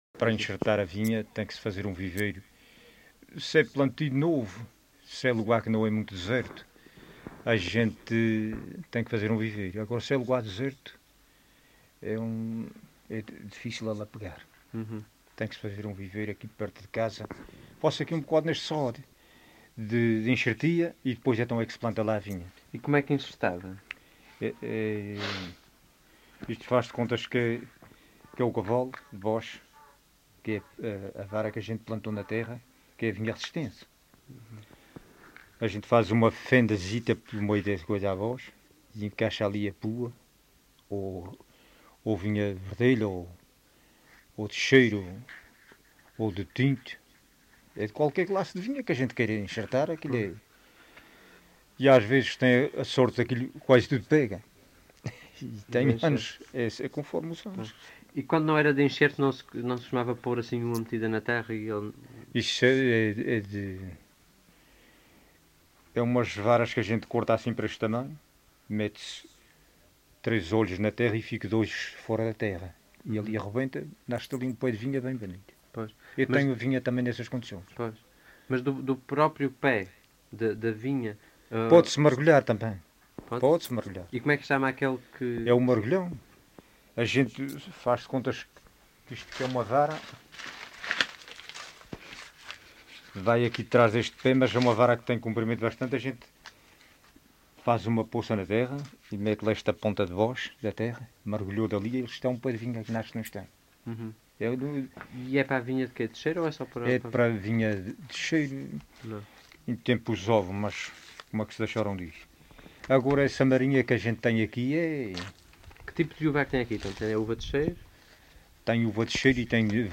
LocalidadeCovas (Santa Cruz da Graciosa, Angra do Heroísmo)